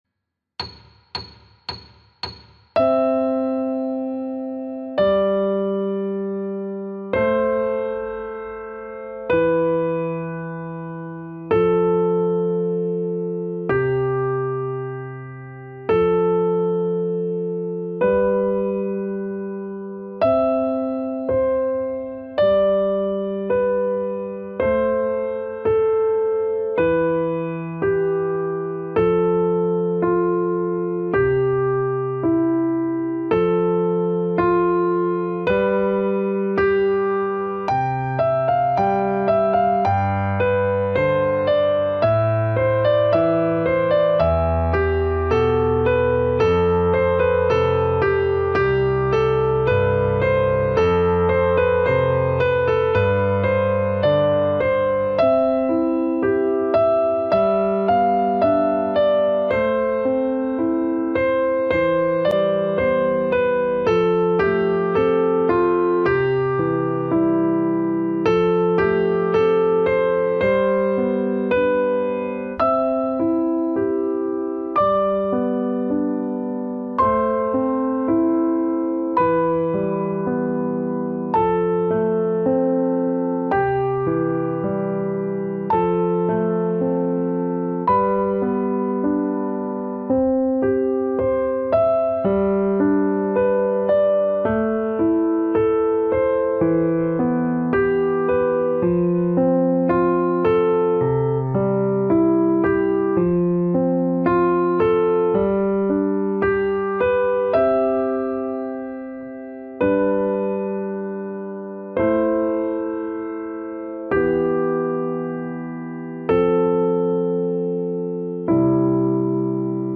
Canon-obe-110-metronom.mp3